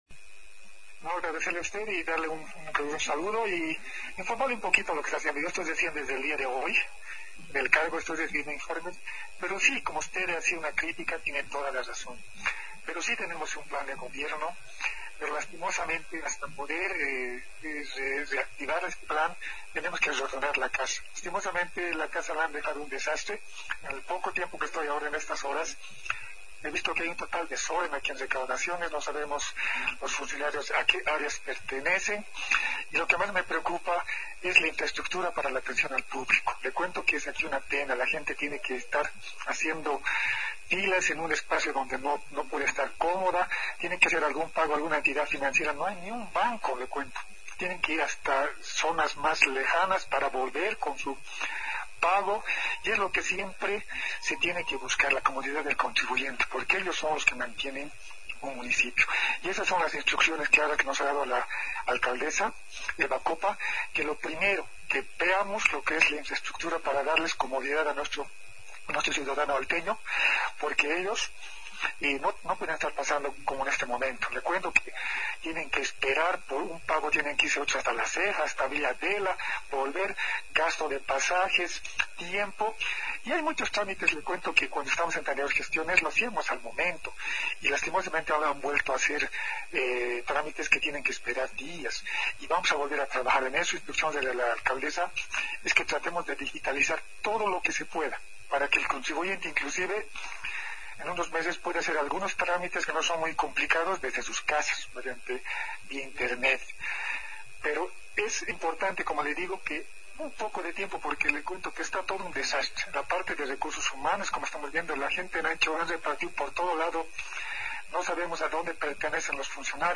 (El Alto, 12 – 05 – 21) El nuevo director de recaudaciones de la alcaldía de la ciudad de El Alto, Lic. Jhon Villalba, en entrevista con Radio Integración, anunció que su autoridad tiene un plan de gobierno para encarar los próximos años sin embargo enfatizó que se debe ordenar la casa para lo cual solicitó un par de semanas.